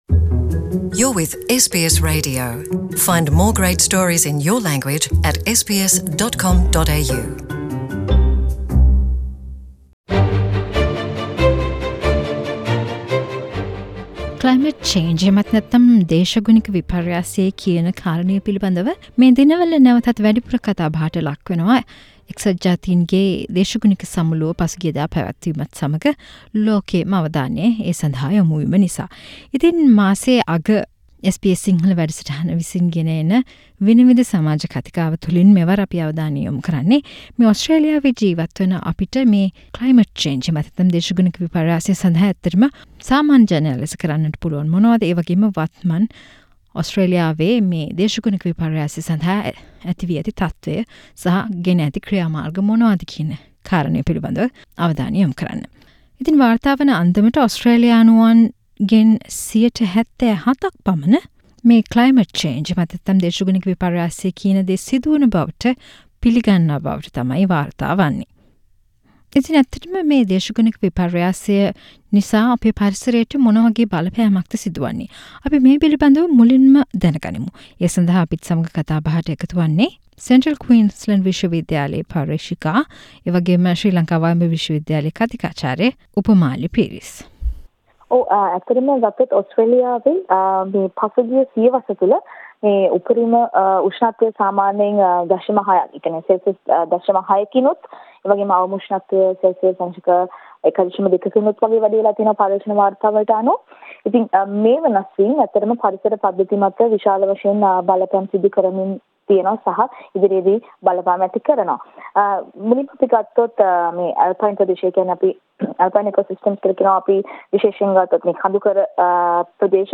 SBS සිංහල ගෙන එන විනිවිද සමාජ කතිකාව: ඔස්ට්‍රේලියාවේ සමාජයට වැදගත් කරුණු පිළිබද මාසික සංවාදය